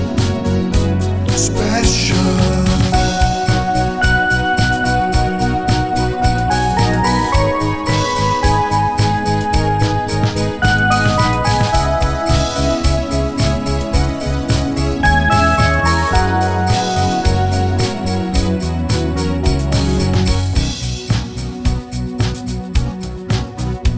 Down 3 Semitones Pop (1980s) 4:28 Buy £1.50